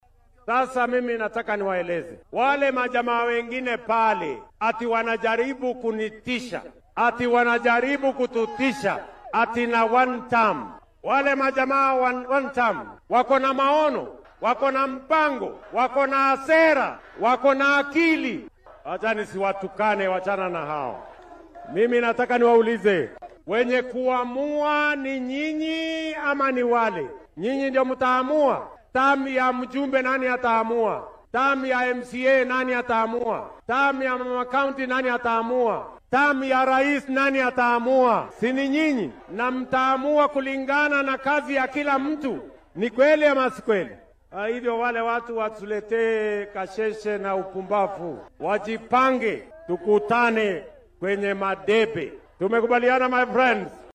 Ruto oo ka hadlayay munaasabadda awoodsiinta dhallinyarada iyo ganacsatada ismaamulka Nairobi oo lagu qabtay aqalka madaxtooyada ee State House ayaa sheegay in bisha soo socoto dowladda iyo bangiga adduunka oo iskaashanaya ay ilaa 5 bilyan oo shilin ku bixin doonaan in min 50,000 oo shilin la siiyo ilaa 100,000 oo dhallinyaro ah.